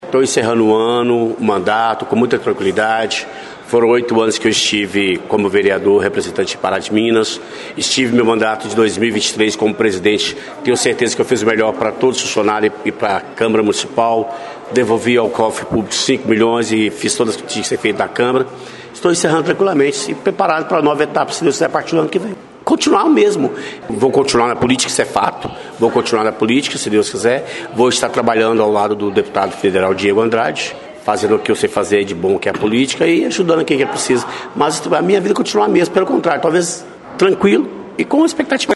Ao ser abordado pela nossa reportagem, Lara fez um rápido balanço de sua passagem pela Câmara Municipal e garantiu que continuará trabalhando na política.